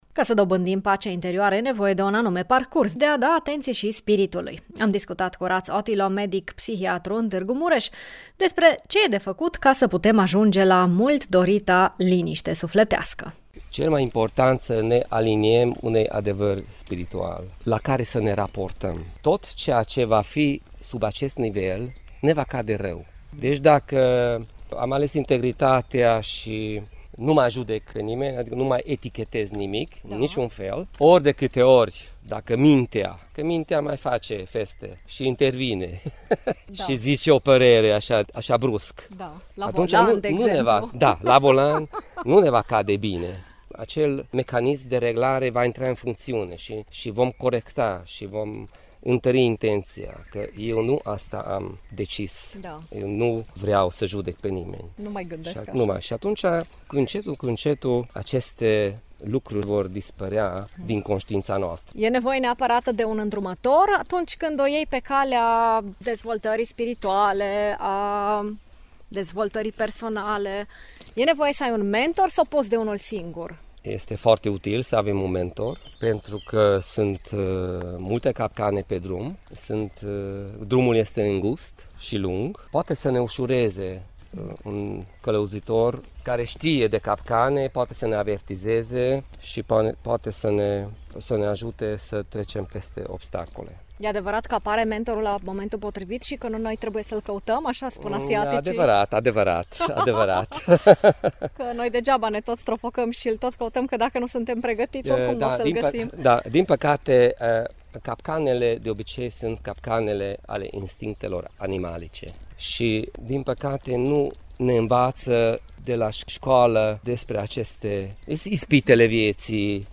medic psihiatru